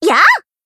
BA_V_Yukari_Battle_Shout_3.ogg